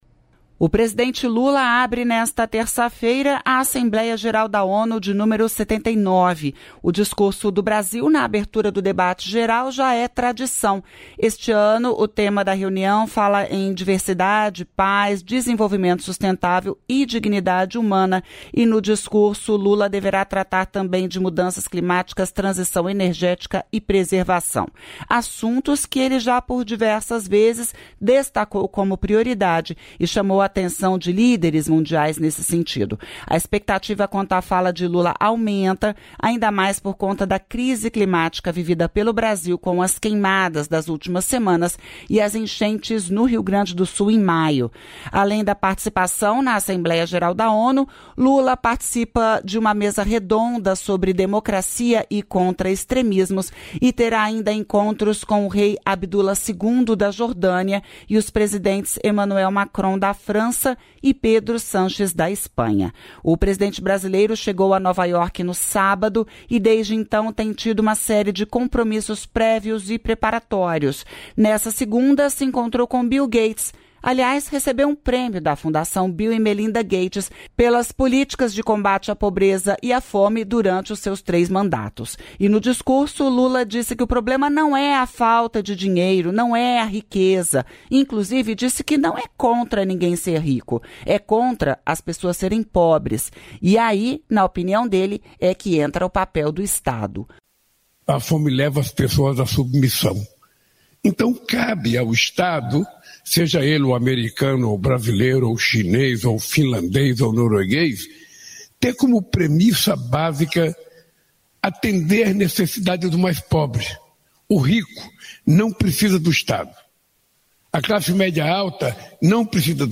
Discurso
Lula discursa na abertura da Assembleia Geral da ONU nesta terça-feira